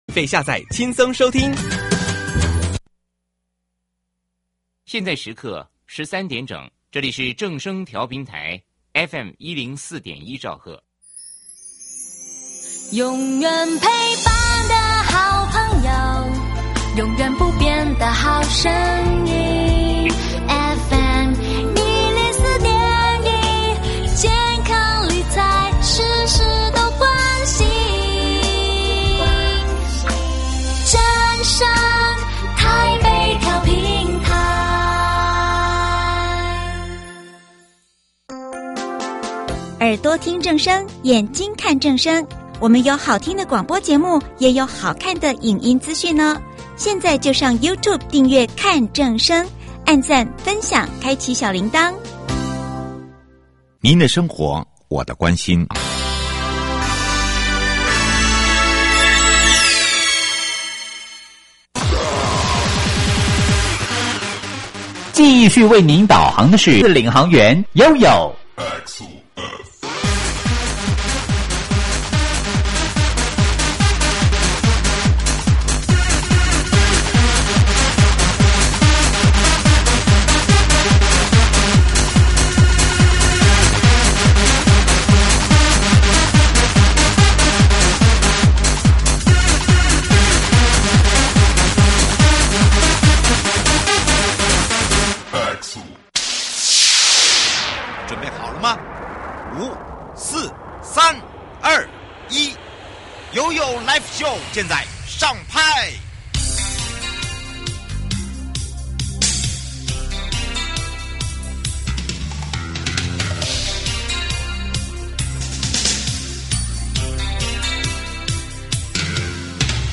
受訪者： 1.國土署都市基礎工程組